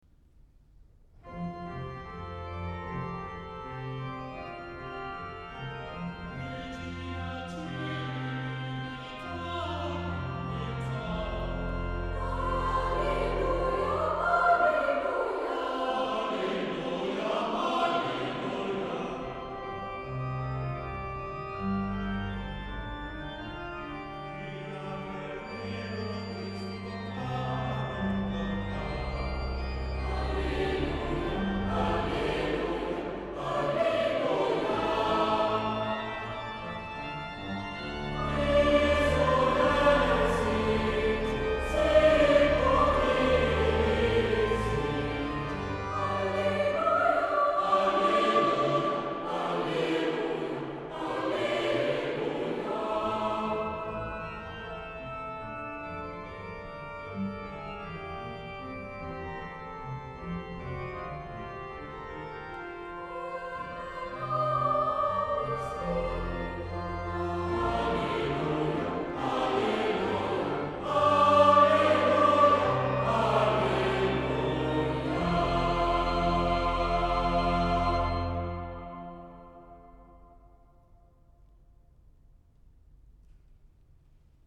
Koormuziek
orgel
trompet
hoorn
trombone